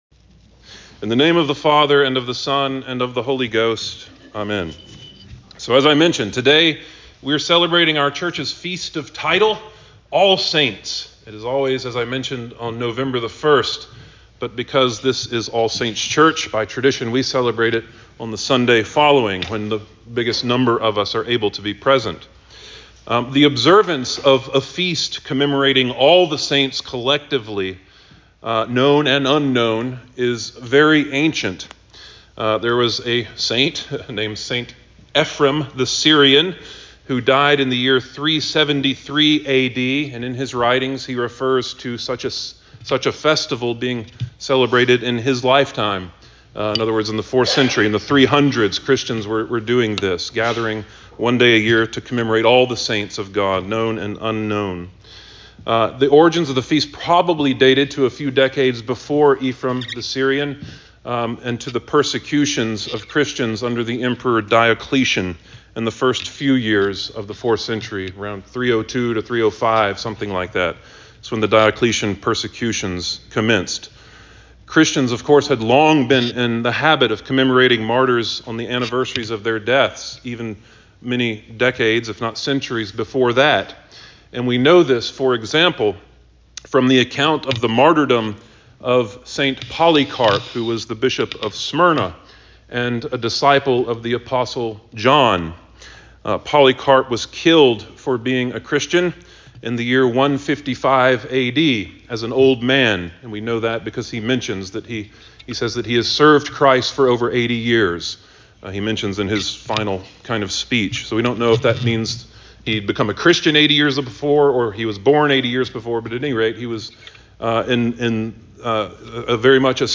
All Saints Sunday Sermon 11.05.23